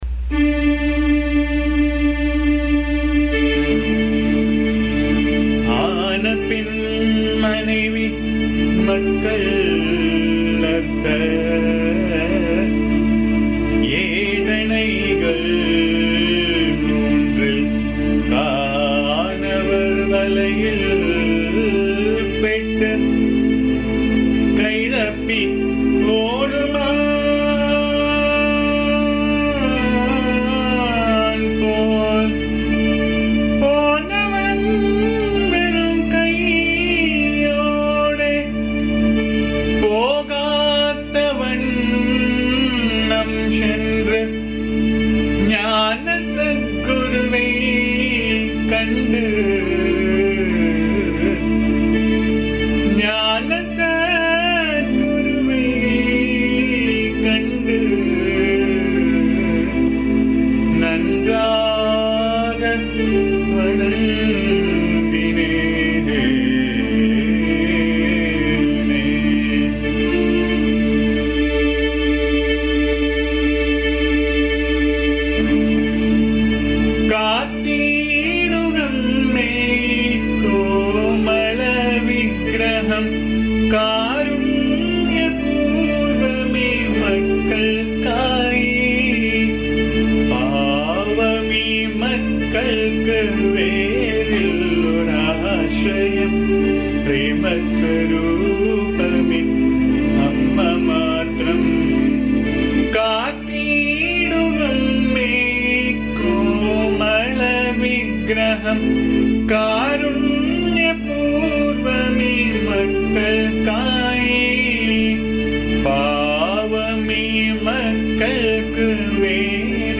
This is a very beautiful song set in Anandabhairavi Raga. This song speaks about a devotee's only request to AMMA which is protection of AMMA's form as that is the only refuge for the devotee.
AMMA's bhajan song